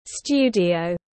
Phòng thu tiếng anh gọi là studio, phiên âm tiếng anh đọc là /ˈstjuː.di.əʊ/.
Studio /ˈstjuː.di.əʊ/